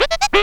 SAX SCRAT06R.wav